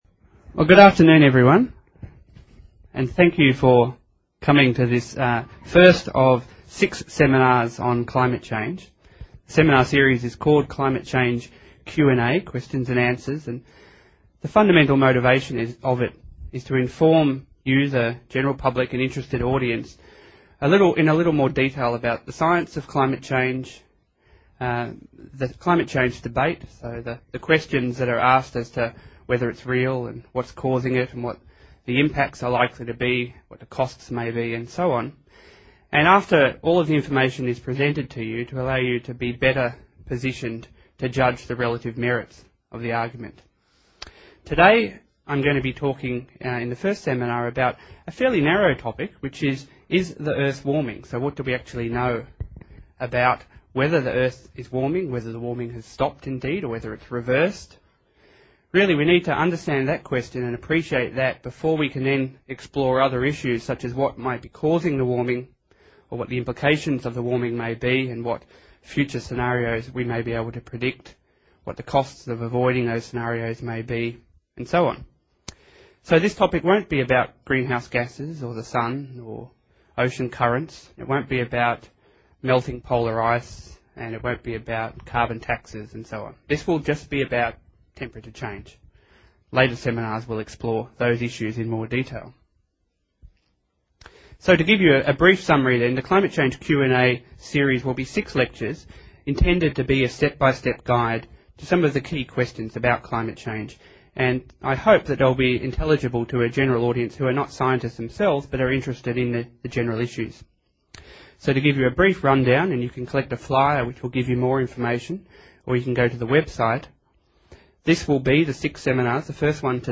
ccqa_seminar1_1of3.mp3